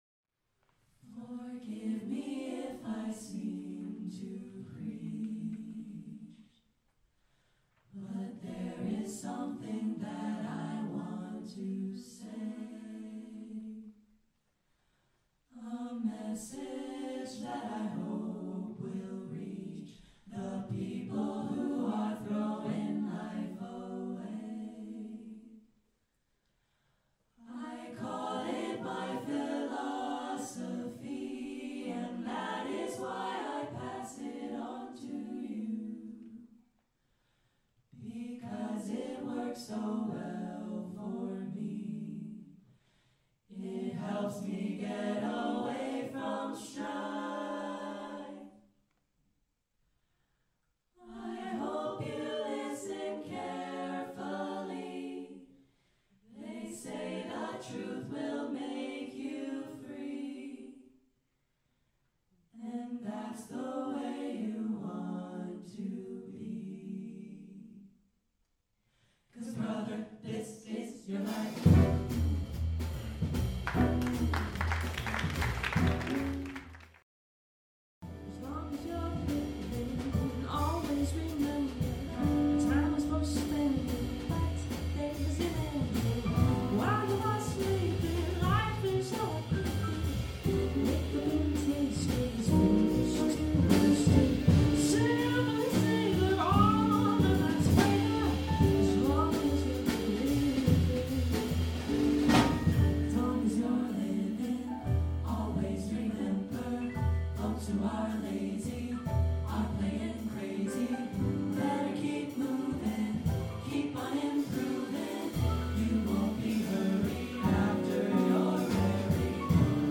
SSATB vocal jazz ensemble arrangement
Check out this live performance of the chart